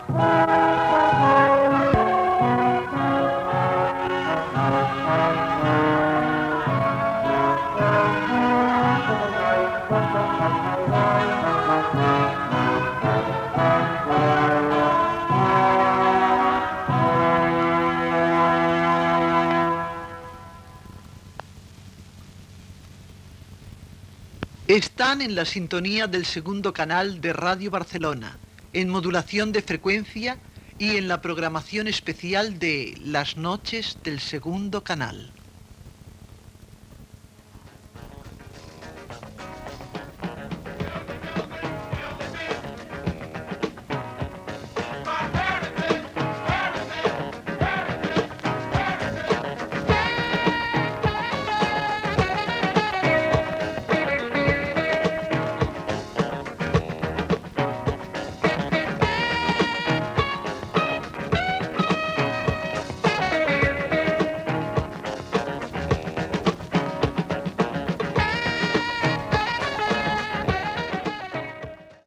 Identificació i tema musical.
Musical
FM